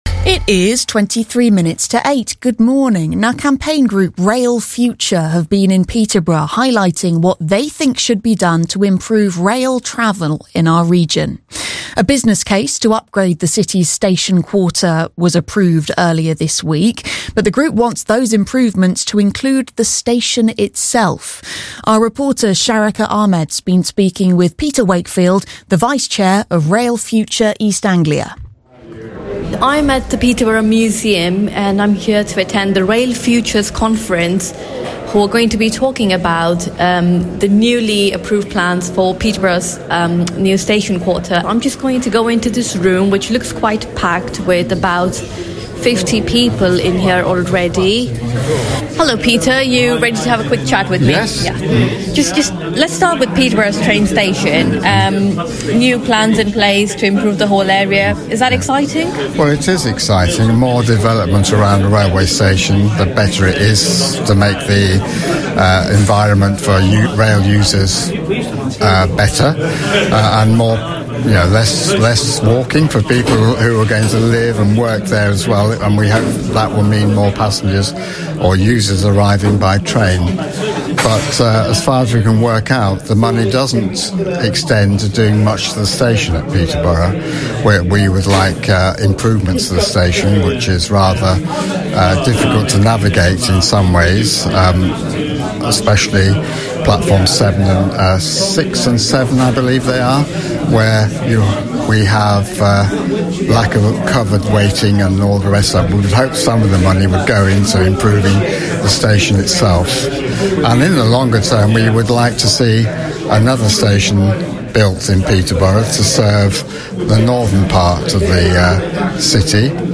Radio Interview] about the conference — BBC Radio Cambridgeshire